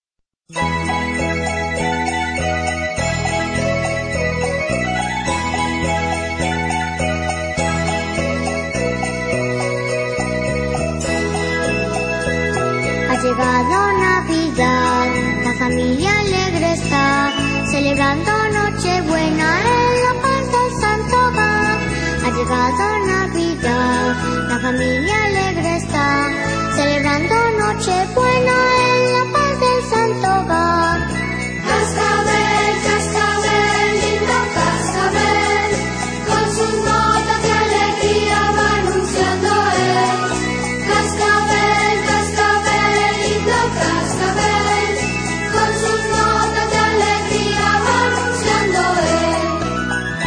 religiosa no liturgica.mp3